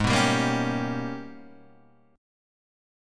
G#mM11 Chord